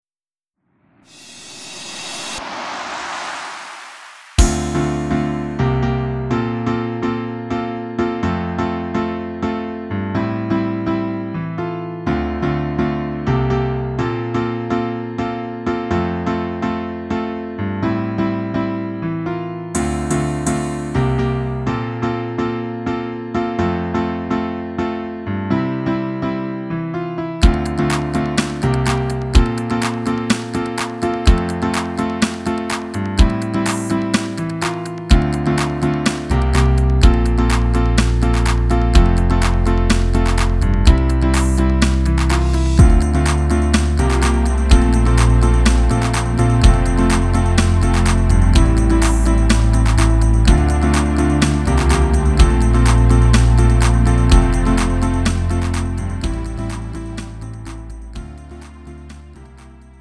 음정 -1키 3:25
장르 구분 Lite MR